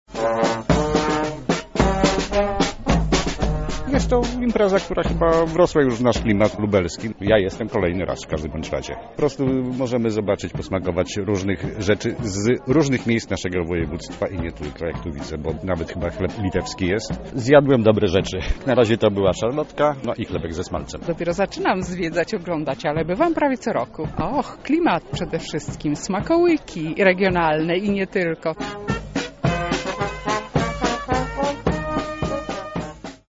Nasza reporterka zapytała uczestników o wrażenia.